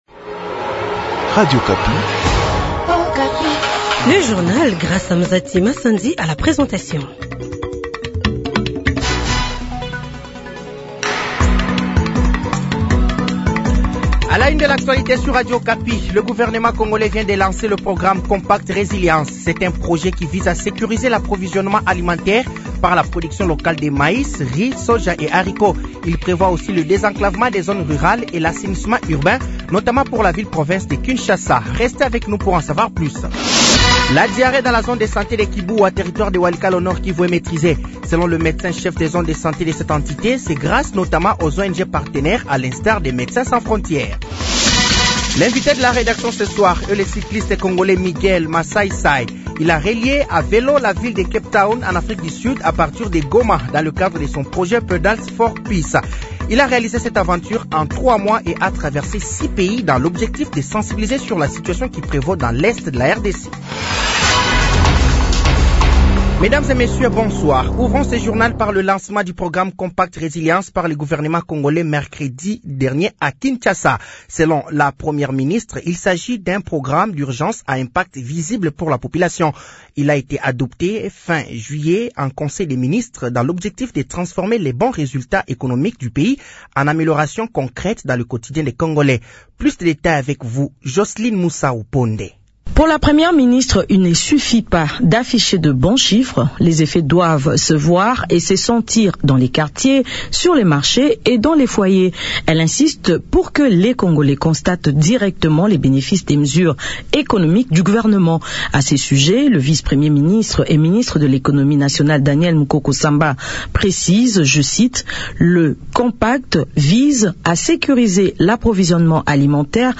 Journal Soir
Journal français de 18h de ce vendredi 25 août 2025